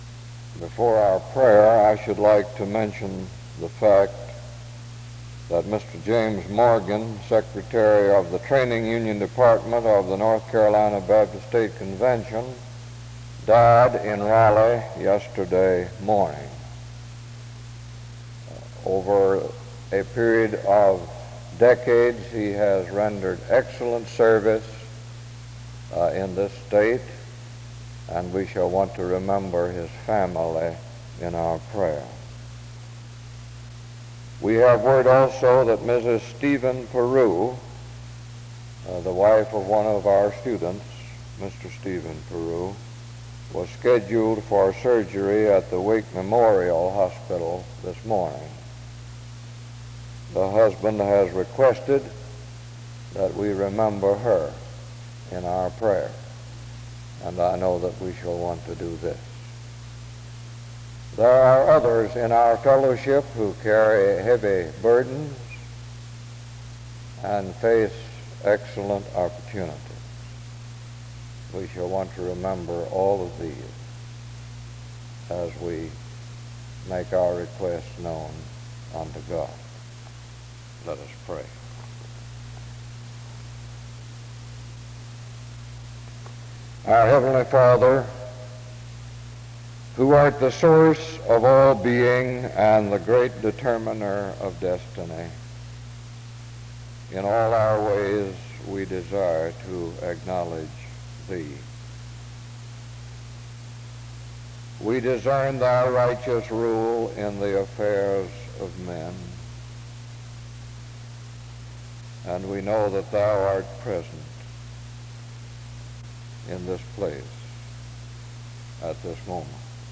The service begins with announcements and prayer from 0:00-4:17. Music plays from 4:19-6:12. A responsive reading takes place from 6:28-8:14.